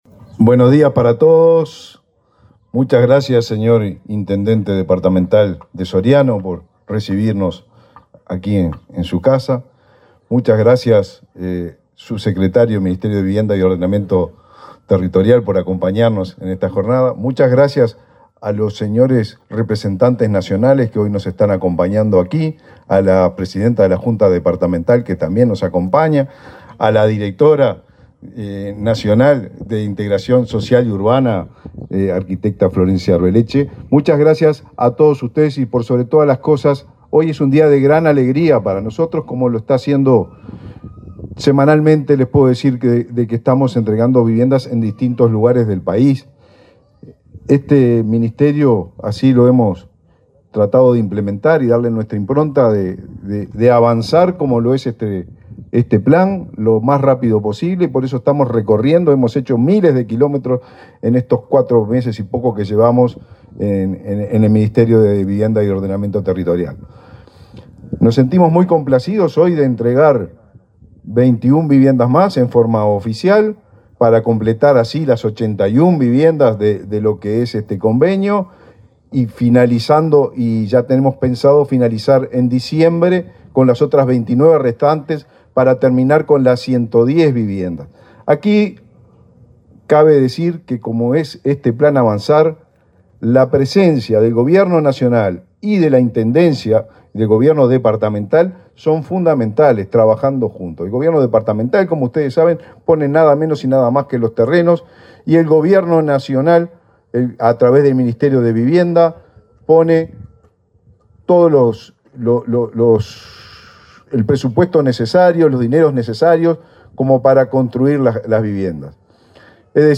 Palabras de autoridades del Ministerio de Vivienda
Palabras de autoridades del Ministerio de Vivienda 06/10/2023 Compartir Facebook X Copiar enlace WhatsApp LinkedIn El ministro de Vivienda, Raúl Lozano, y la directora nacional de Integración Social y Urbana, Florencia Arbeleche, participaron en el acto de entrega de 21 casas para relocalización de familias que viven en zonas inundables de Mercedes, departamento de Soriano.